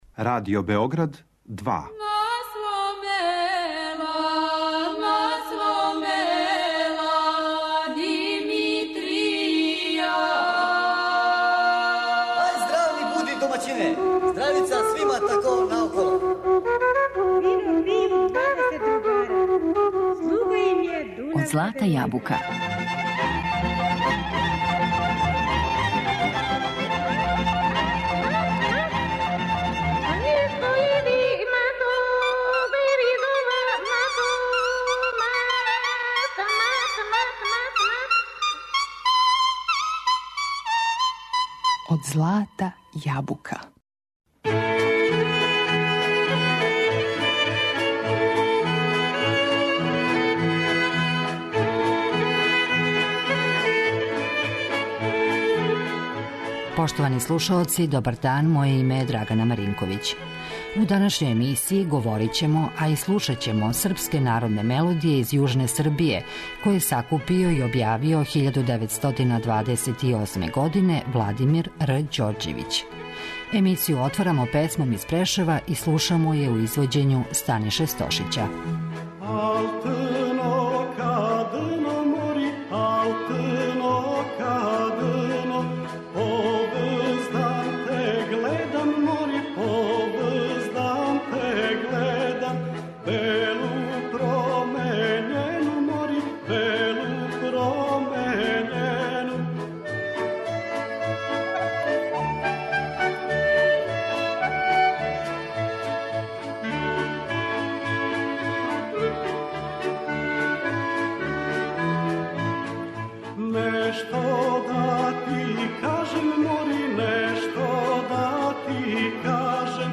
Емисија изворне народне музике